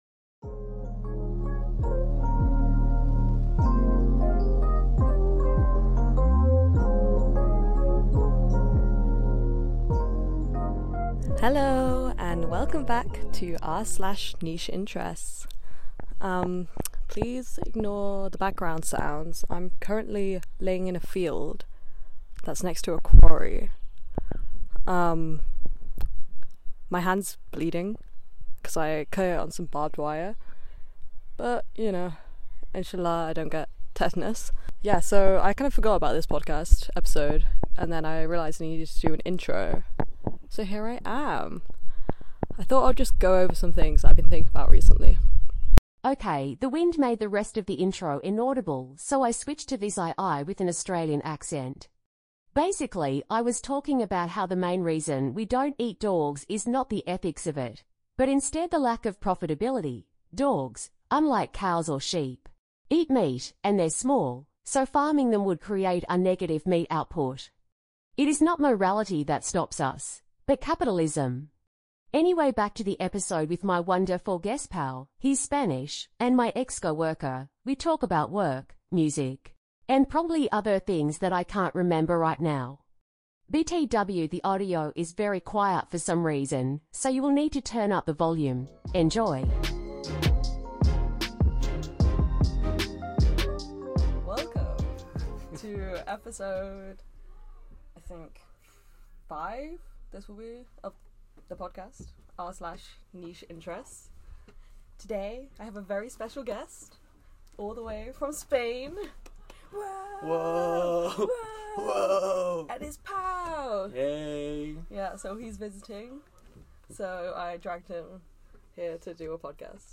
I’m going to be transparent and say this is maybe the worst edited episode I’ve ever made, but please be nice.